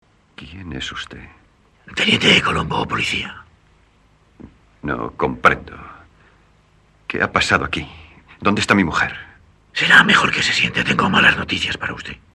En formato también monoaural, este sonido es correcto, con una fidelidad y calidad similar a la de la pista en inglés.
Es interesante destacar que la voz de Colombo en los dos primeros episodios regulares (ambos se encuentran en el primer disco) es claramente diferente a la del resto de episodios, como puede escucharse al comparar
la segunda (del primer episodio piloto de 1968 ubicado en el disco 5, la cual es muy similar a la de los discos 2, 3, 4 y 6).